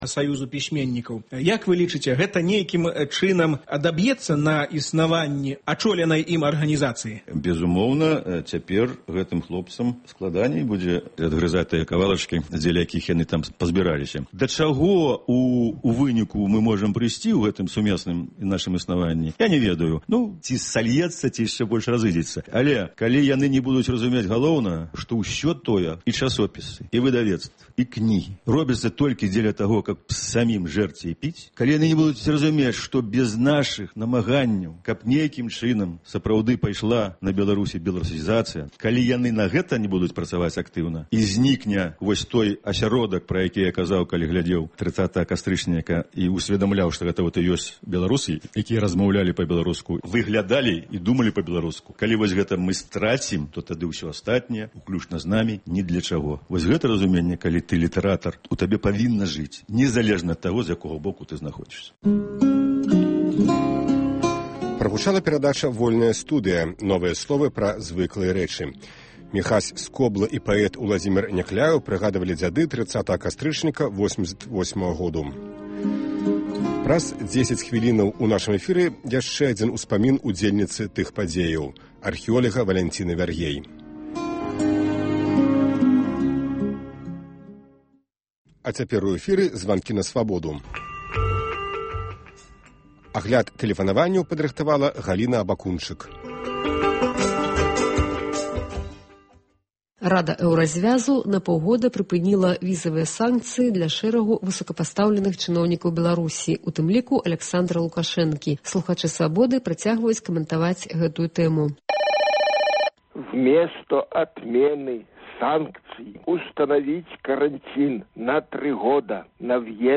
Гутаркі без цэнзуры зь дзеячамі культуры й навукі